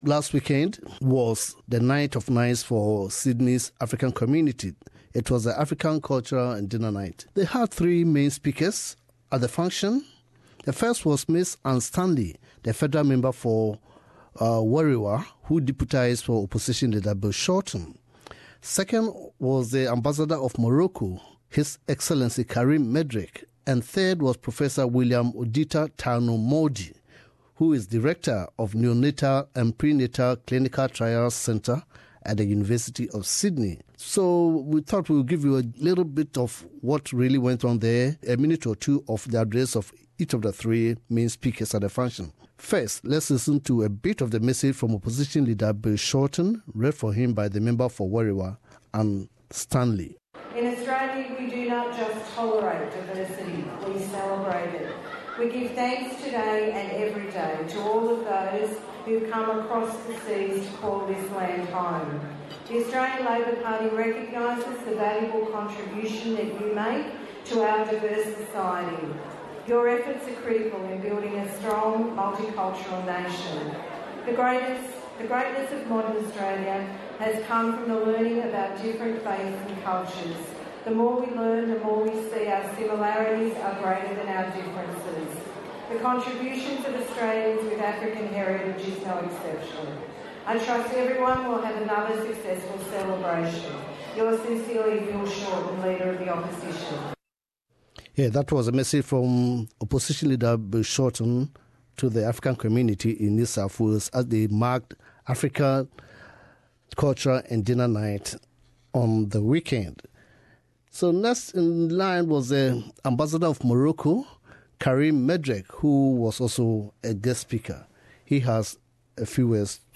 The 2017 African Cultural & Dinner Nite in Sydney didn't disappoint.Three great speakers gave revellers some issues to ponder over while food, drinks and entertainment sprinkled the night's program.